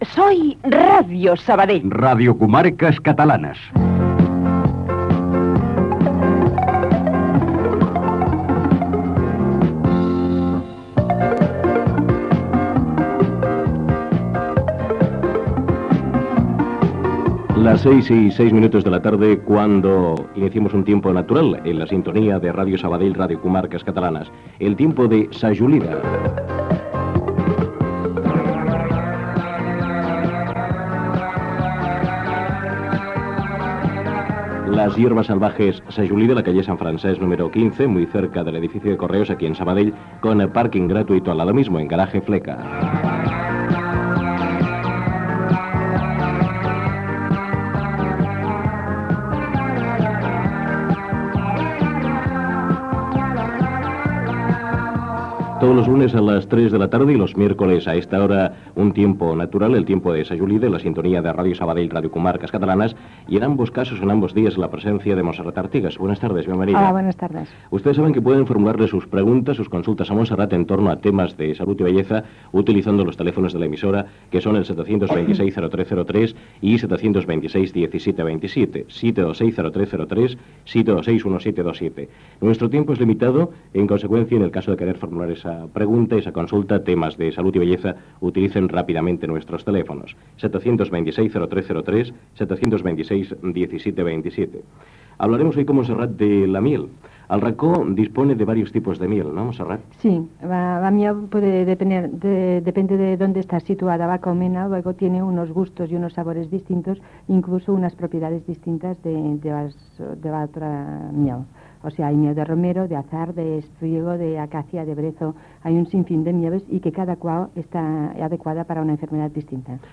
Indicatiu, publicitat, telèfons de l'emissora, consultes telefòniques a l'espai Herbes del racó